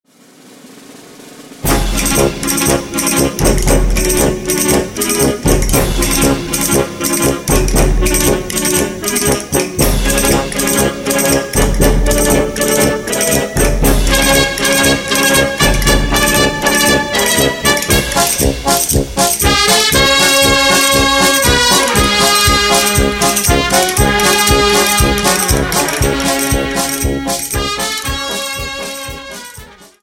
Dance: Paso Doble Song